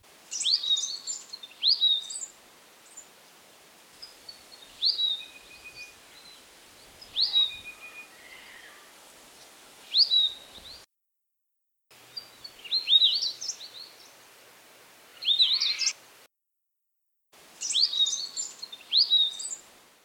weebill.mp3